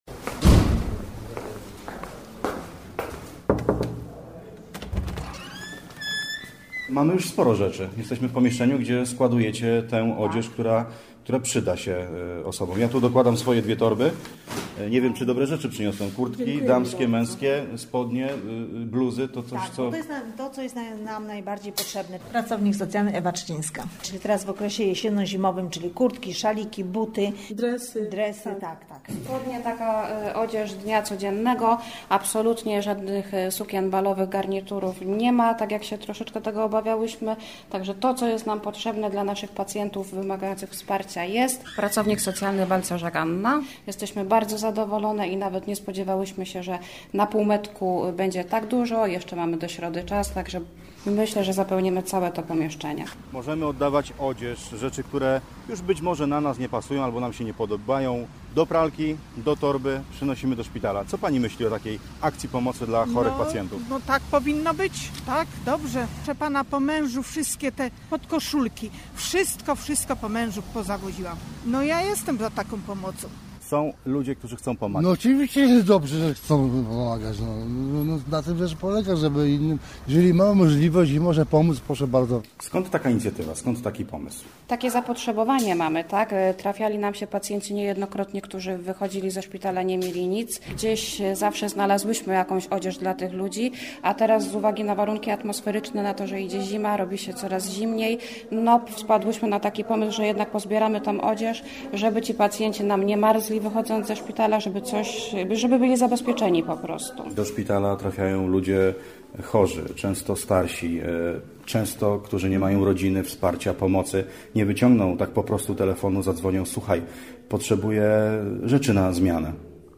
Z potrzebną odzieżą do szpitala pojechał również nasz reporter.